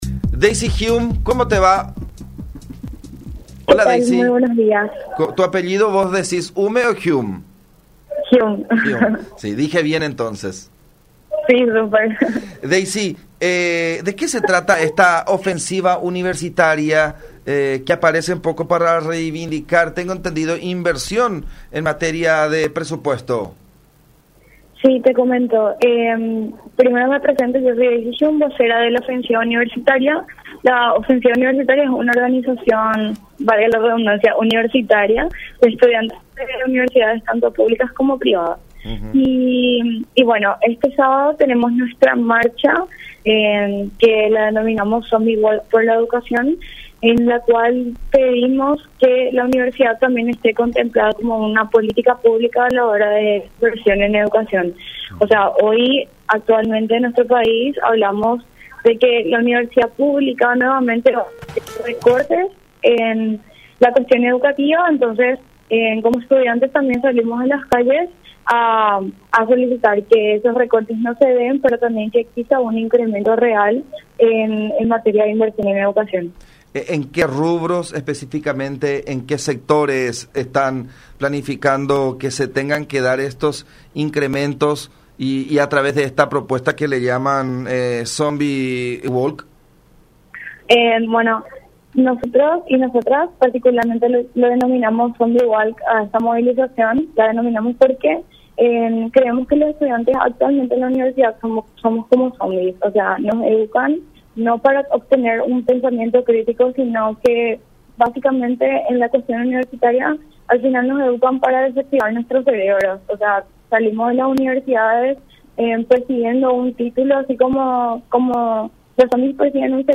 “Nos educan solamente para desactivar nuestro cerebro. No hay una enseñanza, especializaciones que puedan generar mejor efecto en los estudiantes”, criticó en comunicación con La Unión.